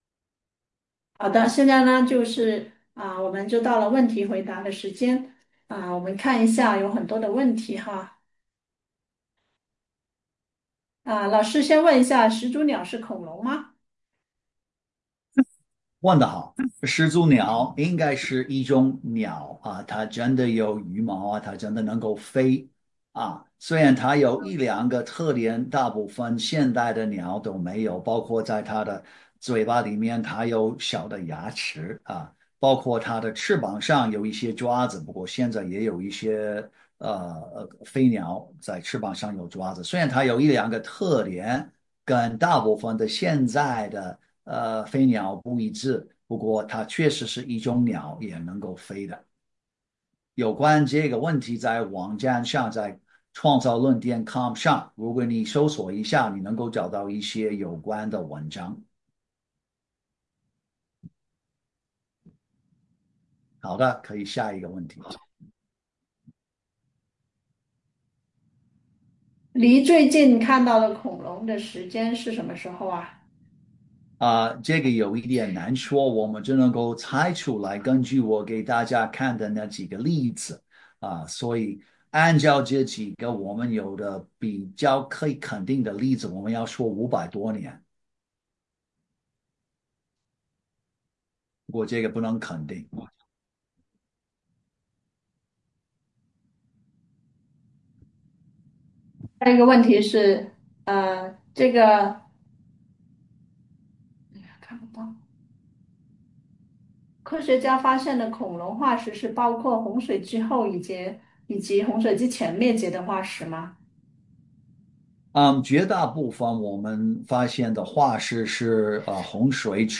《真实的恐龙历史》讲座直播回放
The-true-history-of-the-dinosaur-q&a.mp3